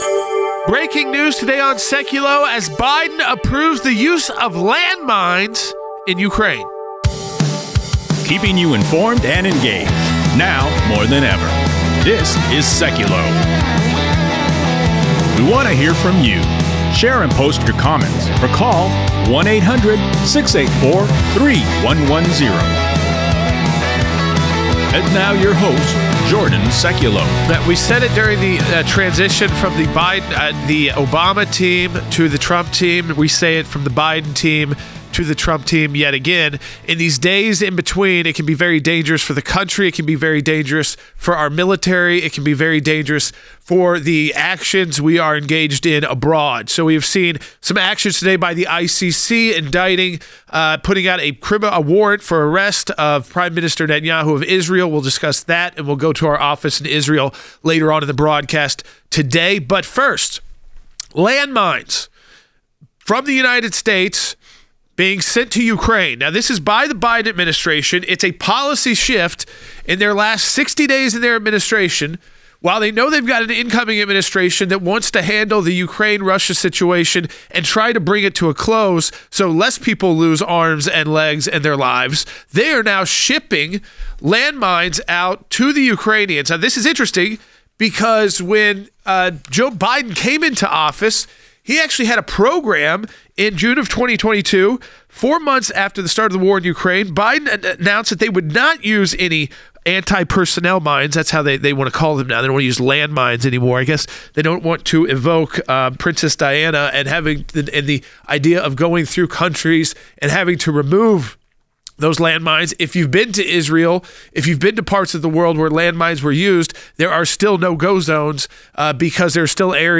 National radio personality Ben Ferguson joins as guest co-host.